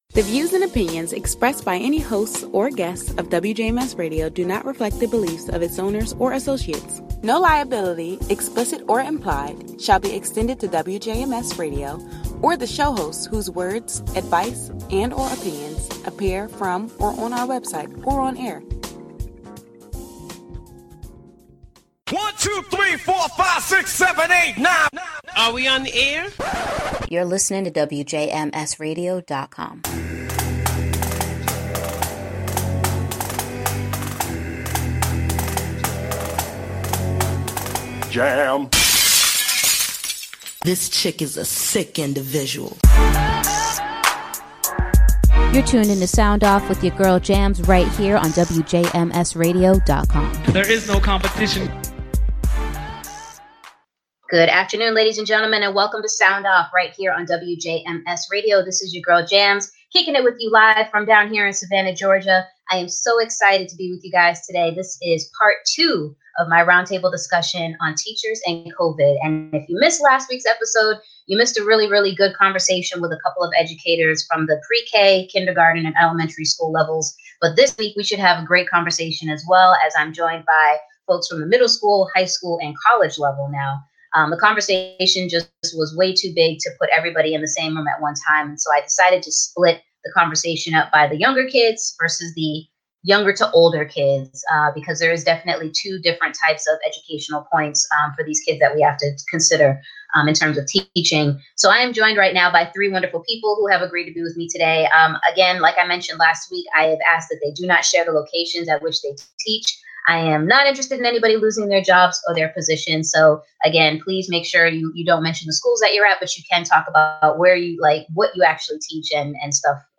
continues her timely conversation about COVID-19 and the upcoming school year with middle school, high school and college professors.